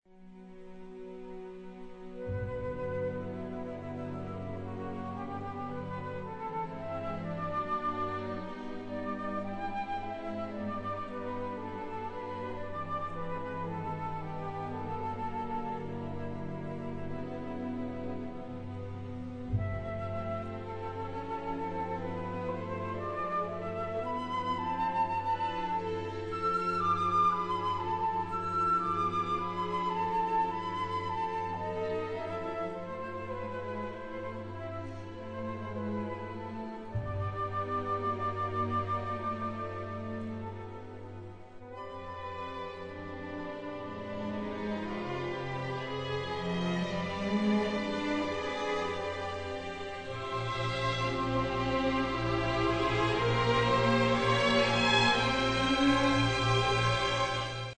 Molto moderato)